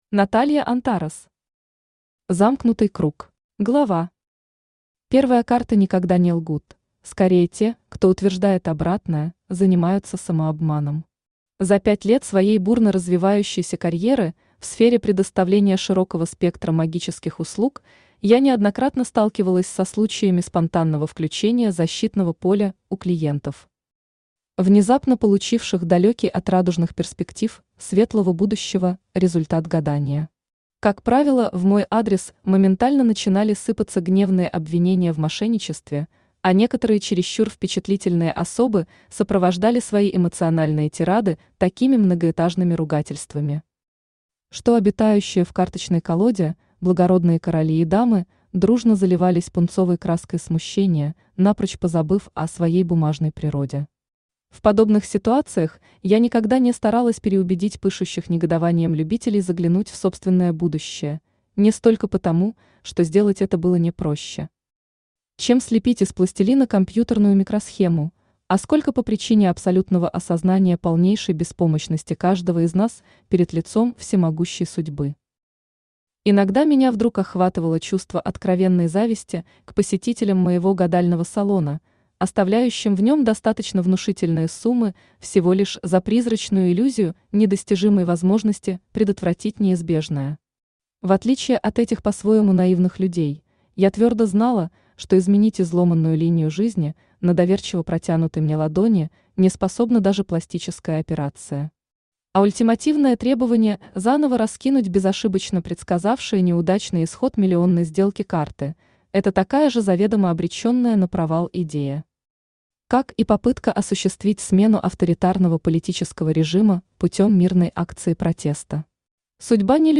Аудиокнига Замкнутый круг | Библиотека аудиокниг
Aудиокнига Замкнутый круг Автор Наталья Антарес Читает аудиокнигу Авточтец ЛитРес.